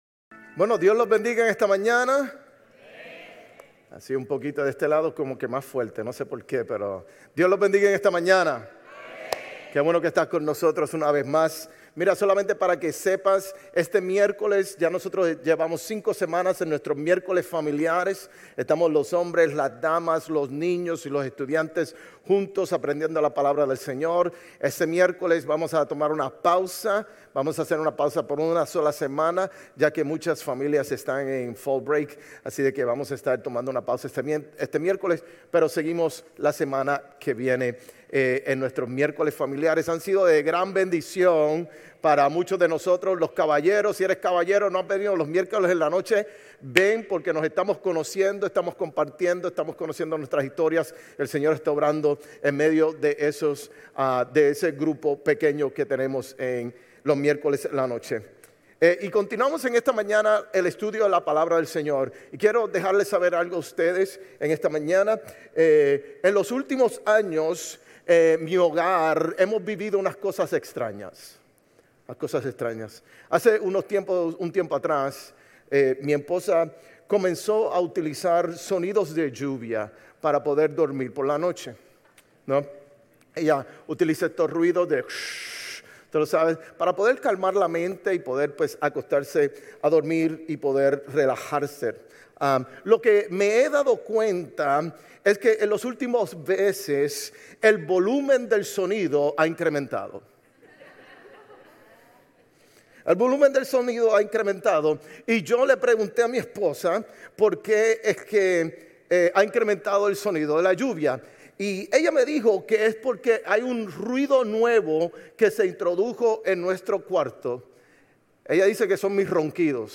Sermones Grace Español 10_12 Grace Espanol Campus Oct 13 2025 | 00:41:44 1x 00:00 / 00:41:44 Subscribe Share RSS Feed Share Link Embed